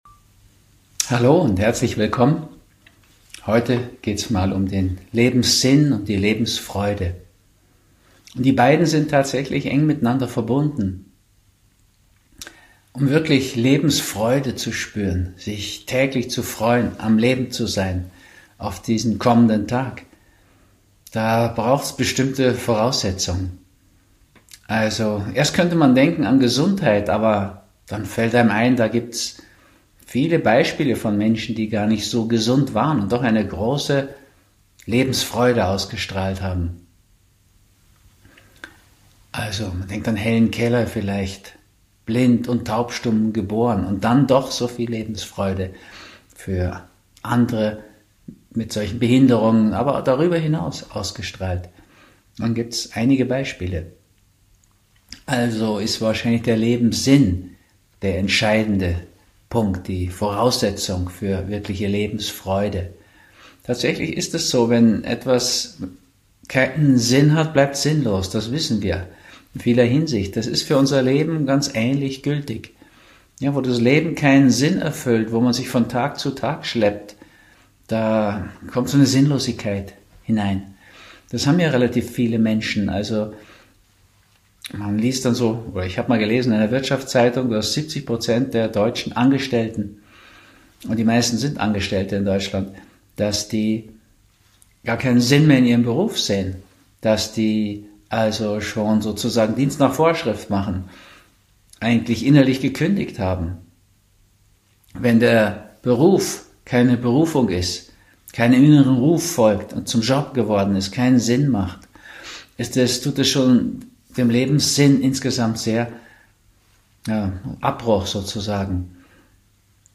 Beschreibung vor 2 Jahren Zentrale Bereiche die in unserem Leben Sinn und Freude machen werden in diesem Vortrag von Dr. Ruediger Dahlke beleuchtet.